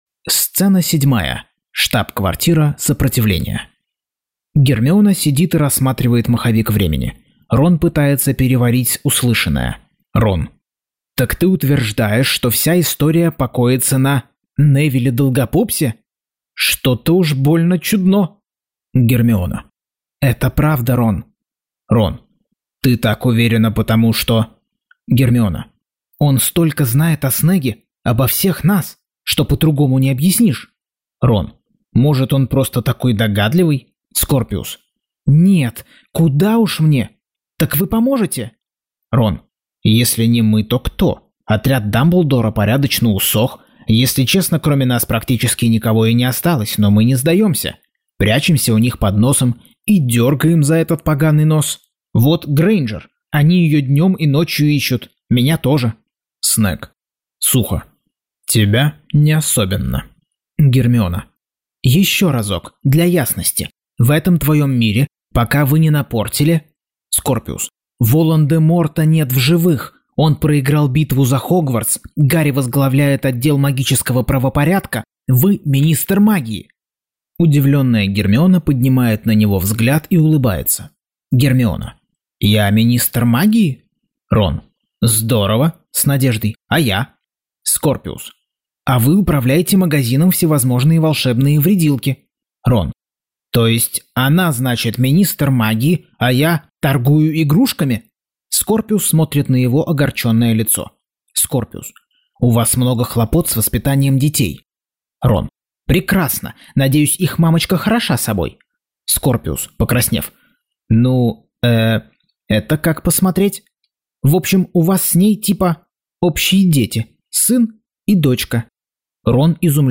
Аудиокнига Гарри Поттер и проклятое дитя. Часть 39.